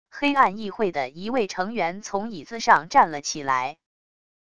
黑暗议会的一位成员从椅子上站了起来wav音频